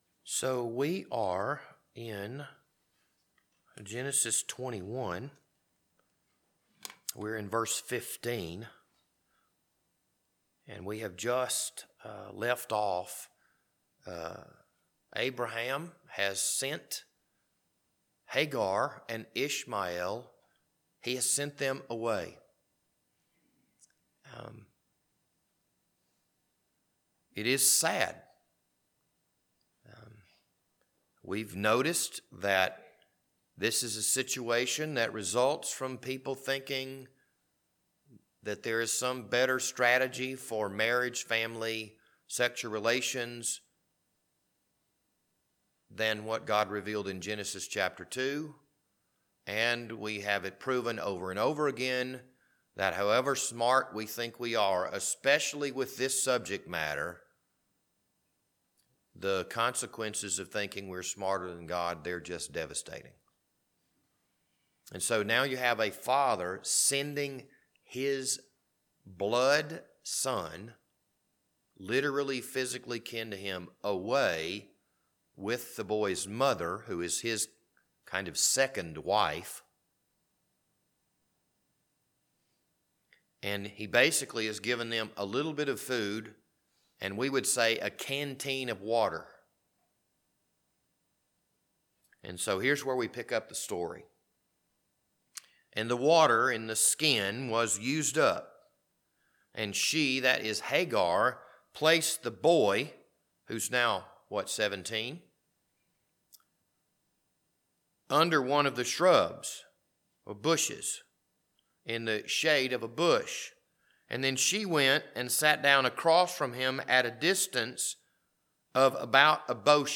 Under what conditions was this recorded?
This Wednesday evening Bible study was recorded on March 22nd, 2023.